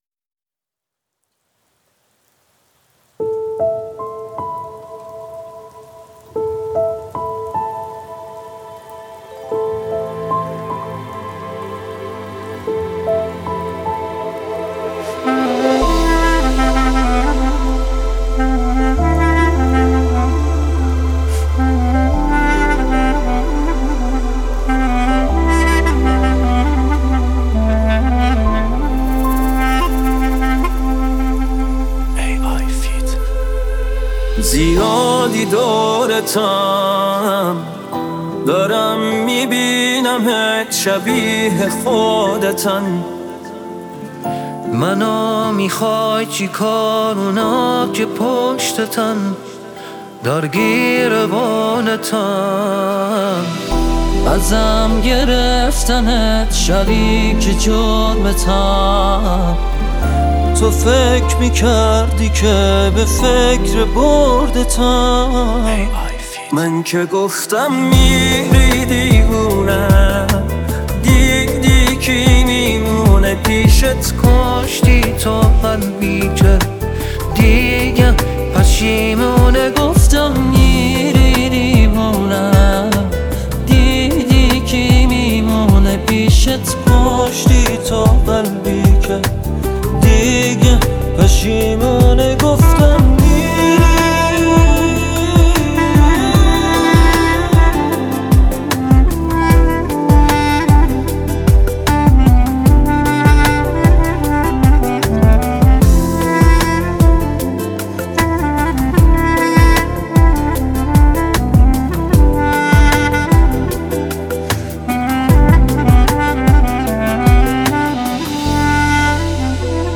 🎶 لذت ببرید از موسیقی ایرانی با بهترین کیفیت!
پخش آنلاین موزیک ریمیکس